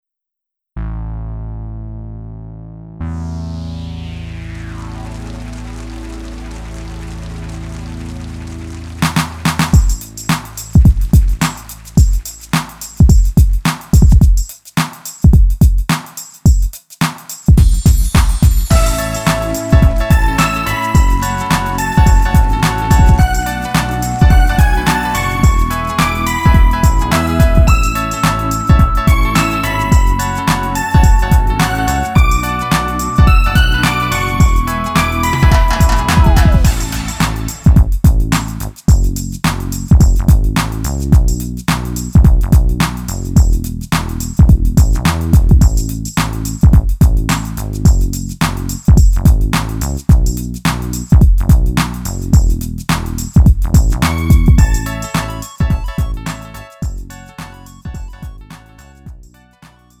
음정 -1키 4:35
장르 가요 구분 Lite MR